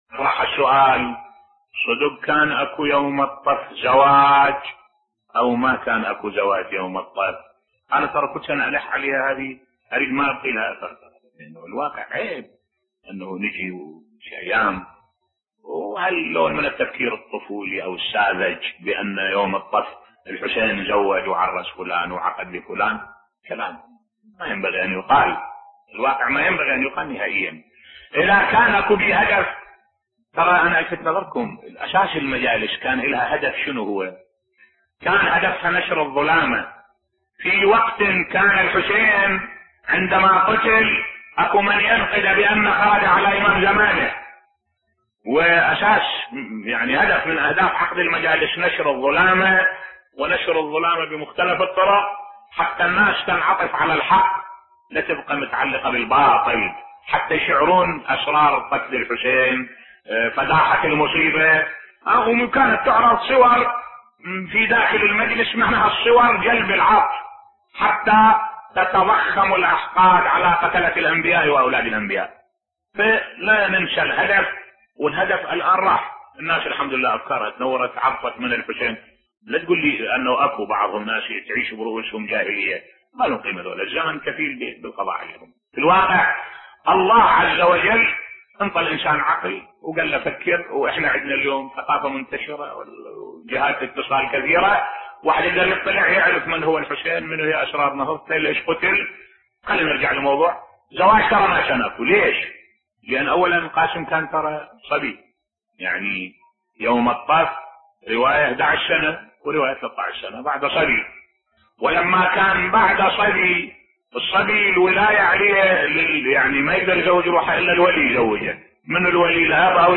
ملف صوتی تفنيد زواج القاسم بصوت الشيخ الدكتور أحمد الوائلي